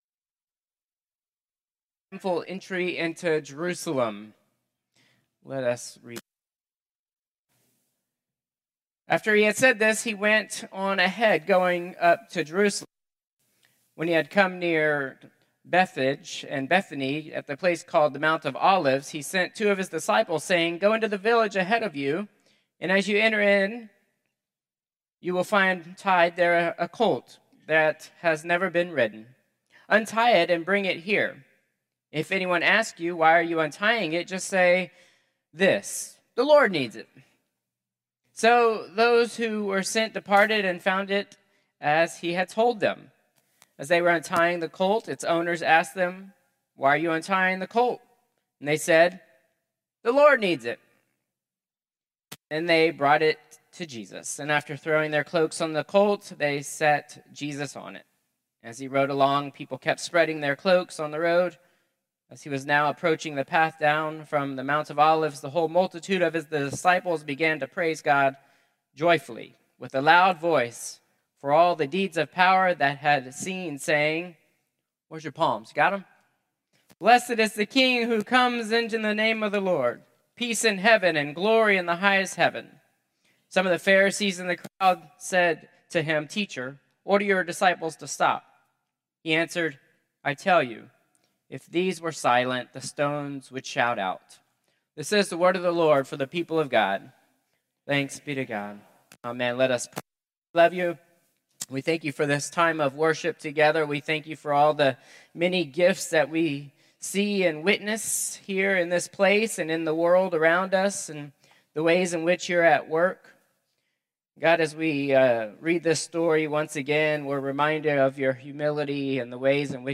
Contemporary Service 4/13/2025